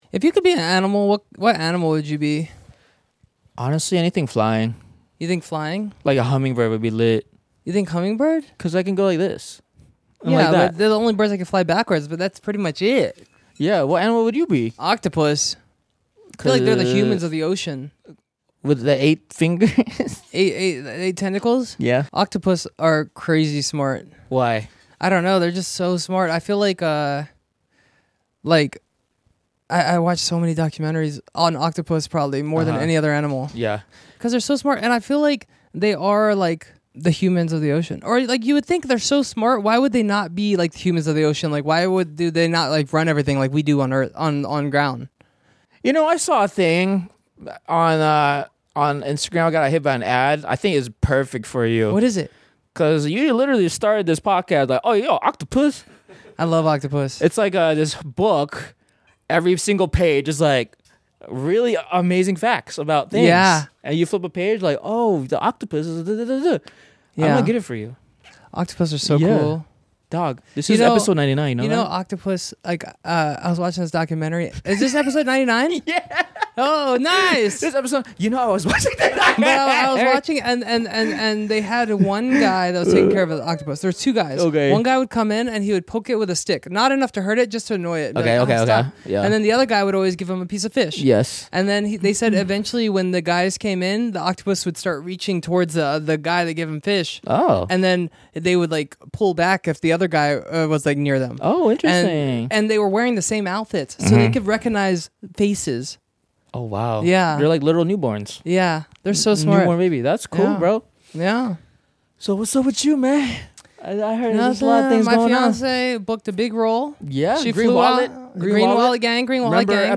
Expect plenty of laughs, unexpected takes, and chaotic moments as they bounce between topics like being a dad, heading back to the Philippines, and even some wild headlines.